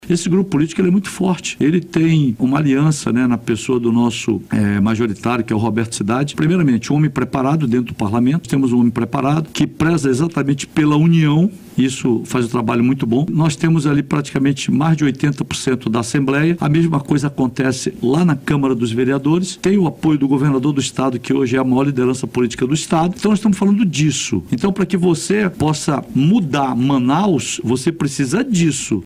Em entrevista no BandNews Amazônia 1ª Edição nesta terça-feira, 06, Menezes negou que tenha provocado o ex-presidente.